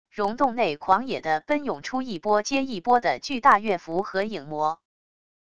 溶洞内狂野地奔涌出一波接一波的巨大月蝠和影魔wav音频